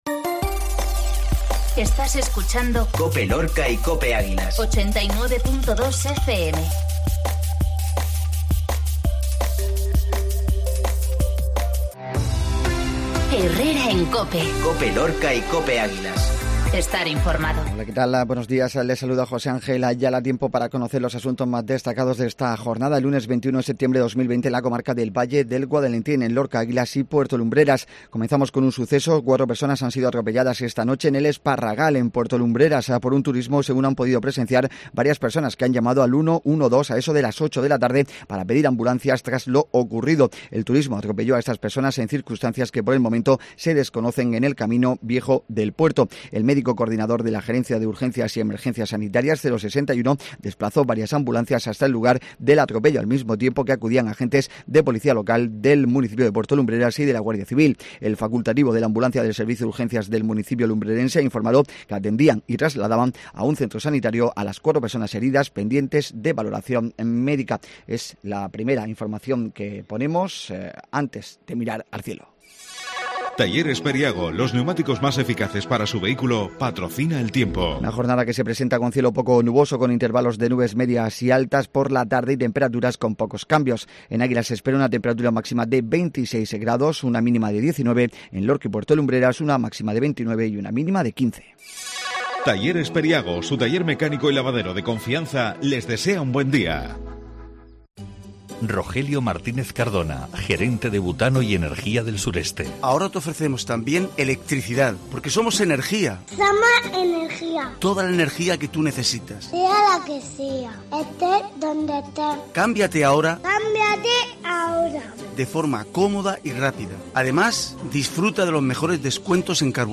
INFORMATIVO MATINAL LUNES 2109